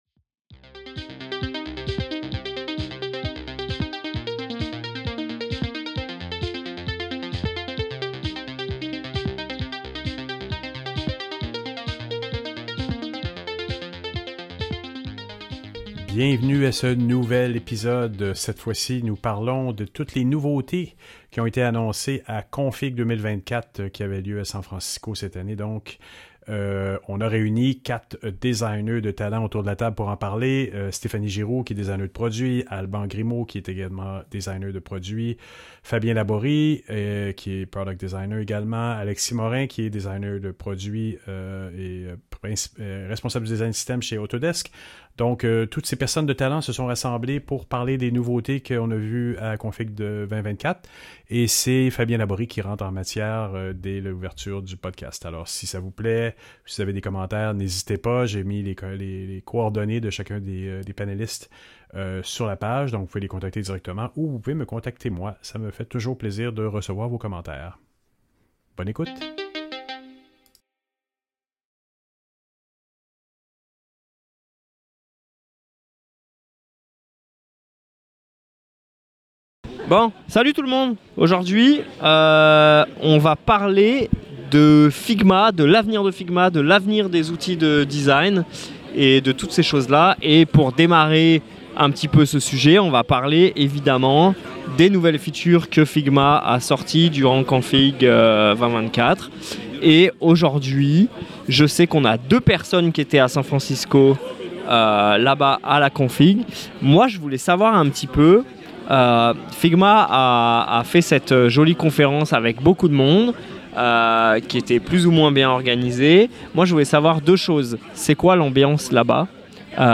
En juin 2024 a eu lieu l’événement Config2024, où plusieurs annonces ont été faites. Dans cet épisode, 4 UX designers très versés dans l’utilisation de Figma sont venus commenter toutes ces annonces face à une pratique en constante évolution.